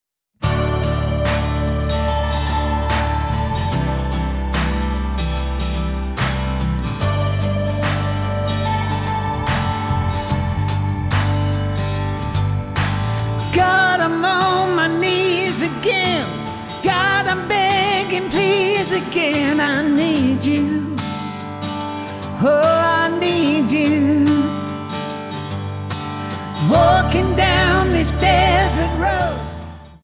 --> MP3 Demo abspielen...
Tonart:D Multifile (kein Sofortdownload.
Die besten Playbacks Instrumentals und Karaoke Versionen .